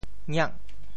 谑（謔） 部首拼音 部首 言 总笔划 16 部外笔划 9 普通话 xuè 潮州发音 潮州 ngiag8 文 中文解释 谑 <动> (形声。
ngiak8.mp3